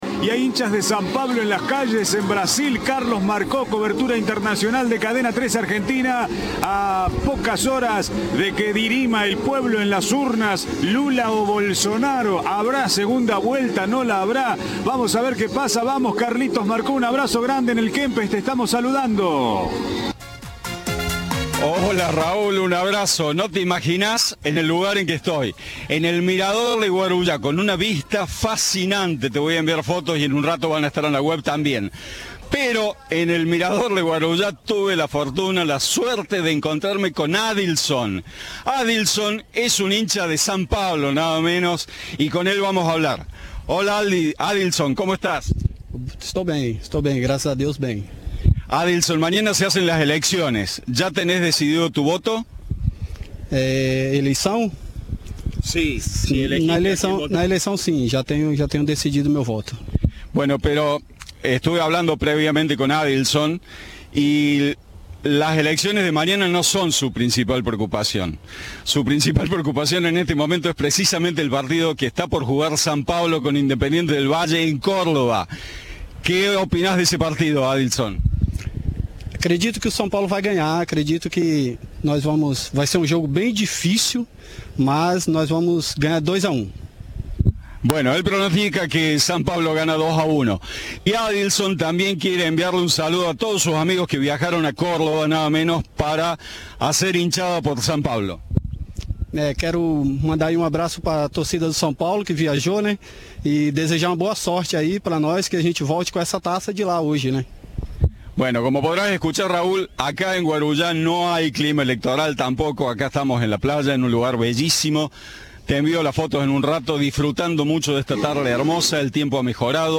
Audio. Un hincha de San Pablo opinó sobre las elecciones y la Copa Sudamericana